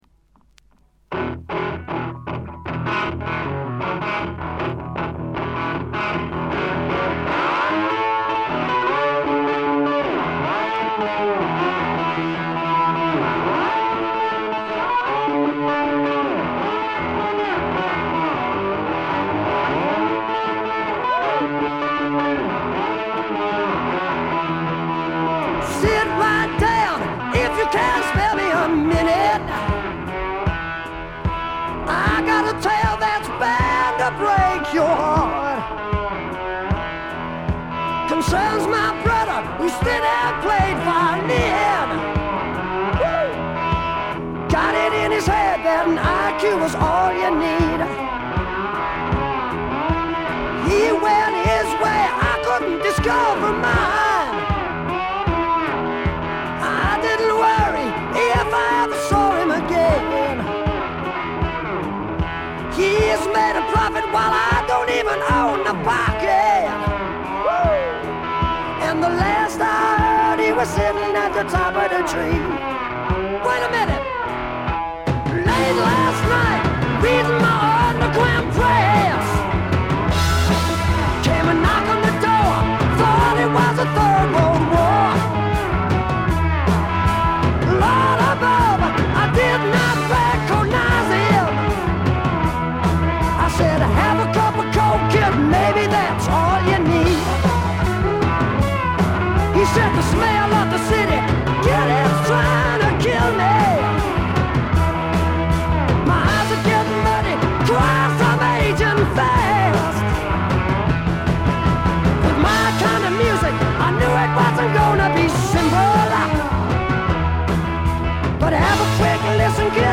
ごくわずかなノイズ感のみ。
試聴曲は現品からの取り込み音源です。